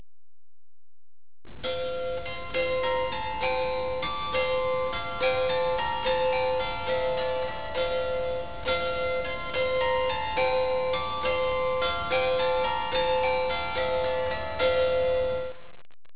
Plucked Psaltery Sound Clips
Each string on this type of psaltery is plucked either with the player's fingernails or with a plectrum.
The example in the photograph, which you can also hear on the sound clip, is a Russian psaltery called a cimbala (from the word cimbalum, which was sometimes used to describe the early family of hammered dulcimers).